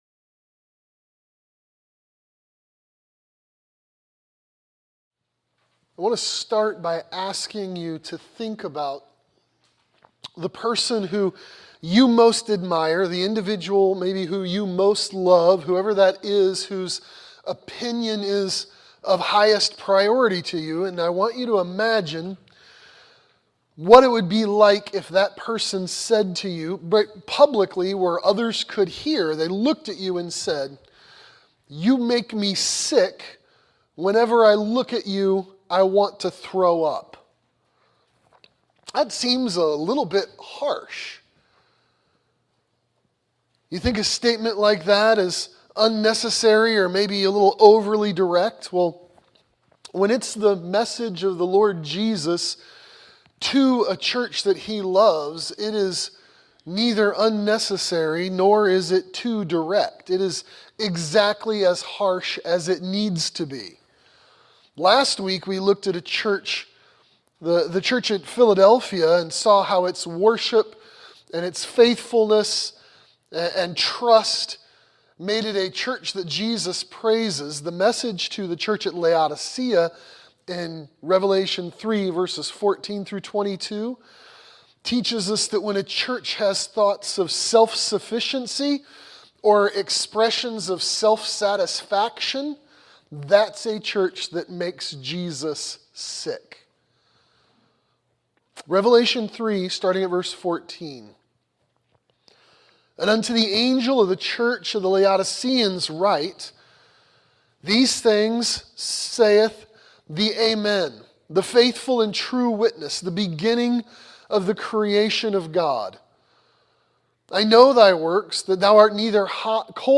A Church That Sickens Jesus | SermonAudio Broadcaster is Live View the Live Stream Share this sermon Disabled by adblocker Copy URL Copied!